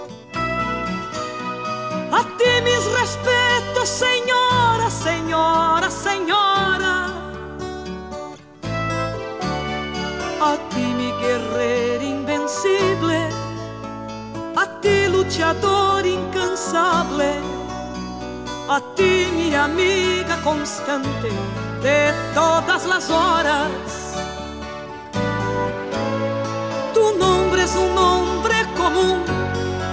Genre: Latino